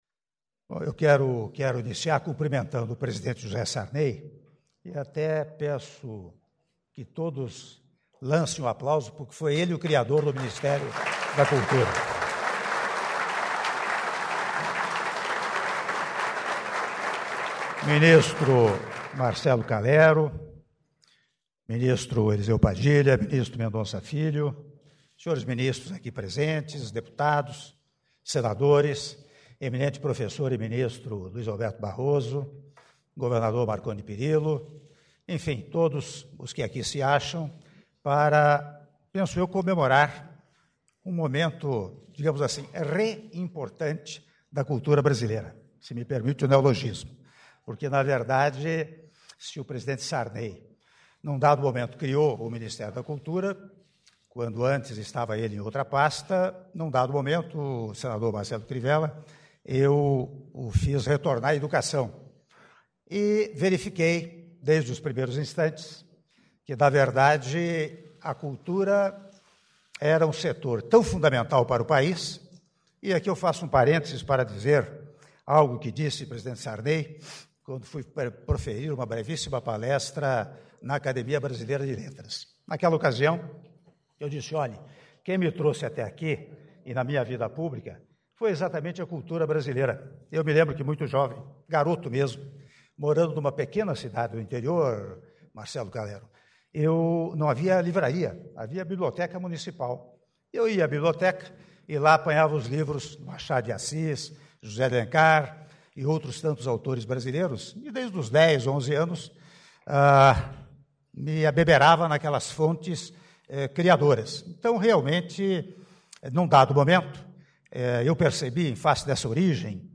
Áudio do discurso do Presidente da República interino, Michel Temer, durante cerimônia de posse do ministro da Cultura, Marcelo Calero - Brasília/DF (06min13s)